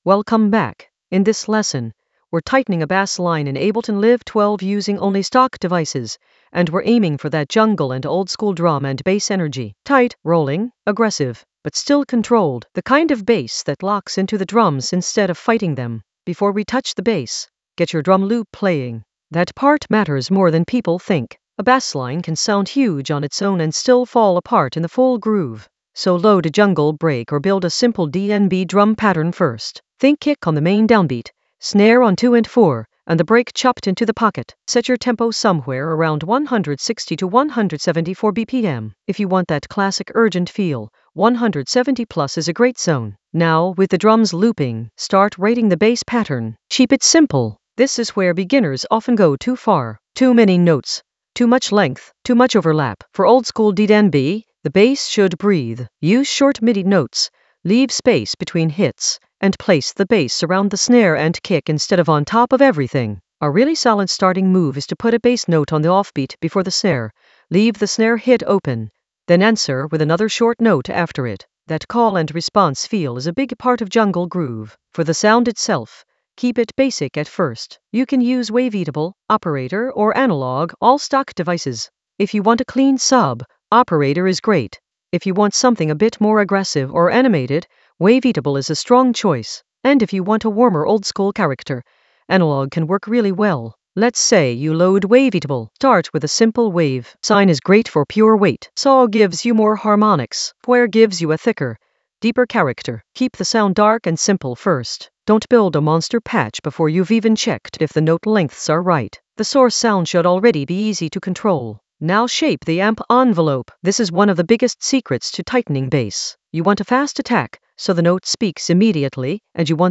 An AI-generated beginner Ableton lesson focused on Tighten a bassline using stock devices only in Ableton Live 12 for jungle oldskool DnB vibes in the Vocals area of drum and bass production.
Narrated lesson audio
The voice track includes the tutorial plus extra teacher commentary.